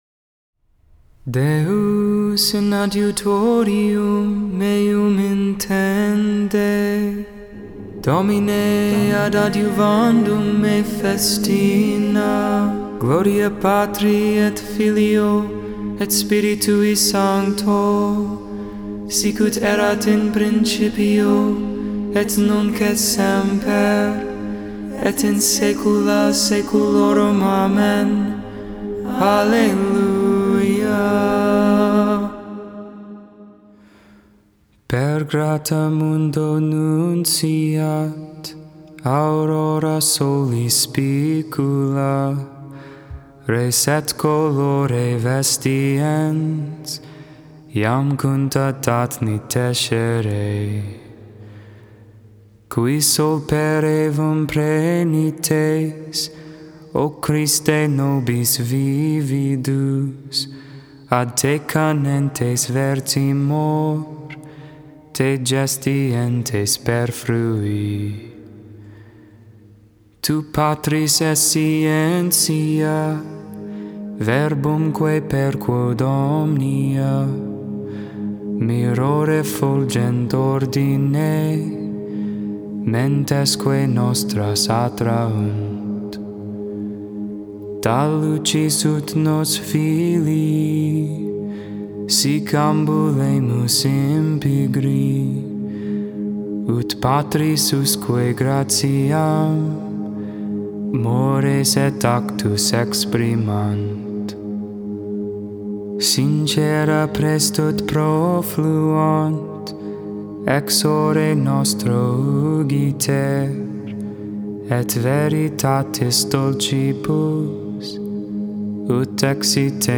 Hymn